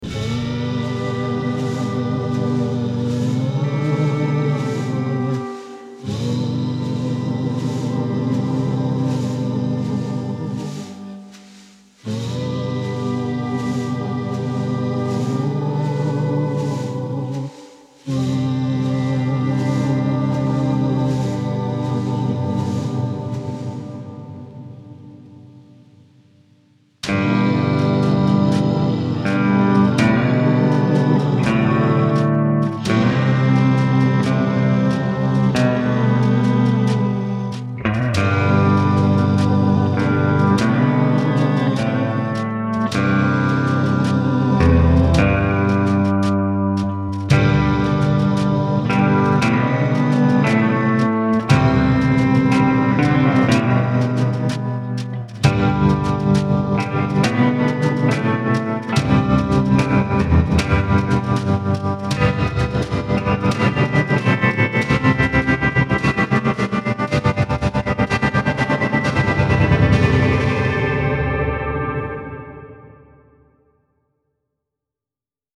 Genre : Bandes originales de films